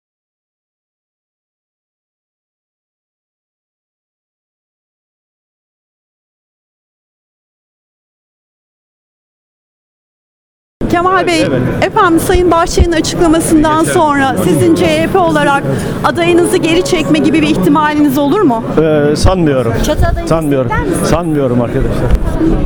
Kemal Kılıçdaroğlu'nun Açıklamaları